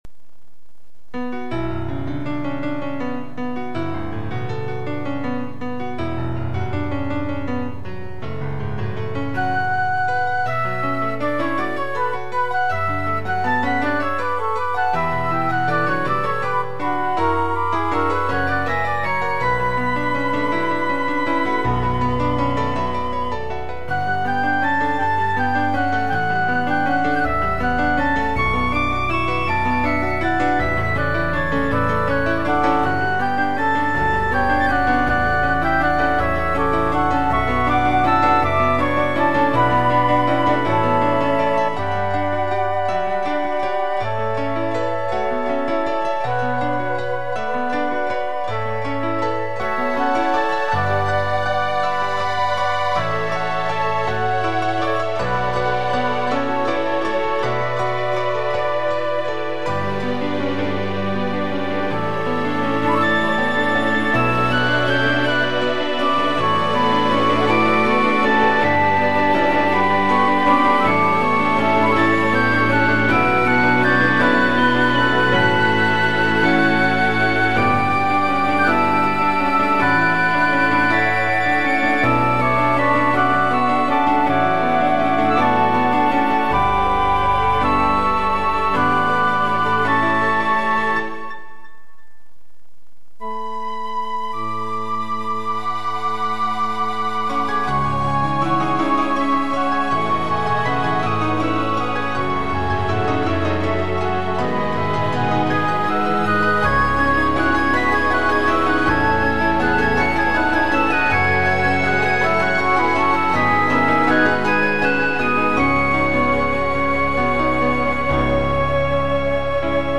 あなたしか 見ていなかったがために 自身が 深い闇の底に落ちてしまったことに 気づかなかった 空はいつだって青く蒼く 綺麗だ 美しい だけど足元の穴にだって 目を向けなきゃだめだ 誰も救えないから 立ち上がれるのは私だけだから 解説 若干音色の違うピアノ2本、フルート2本を重ねています。 これらの掛け合わせと、左右の振り分け演出に手こずりました。